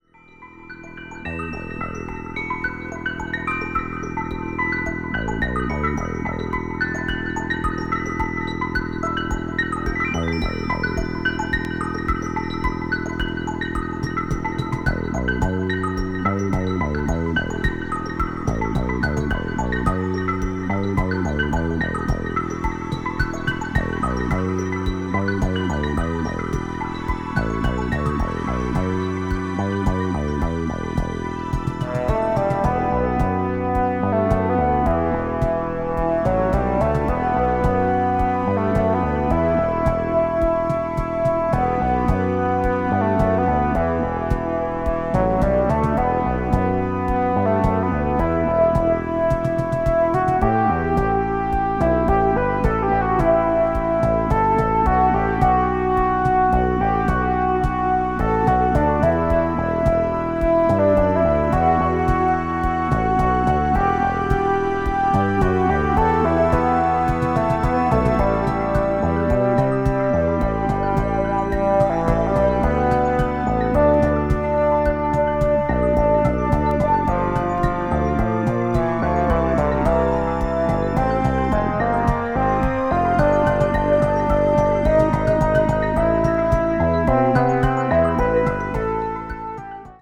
とにかく音が良いです。
electronic   new age   oriental   synthesizer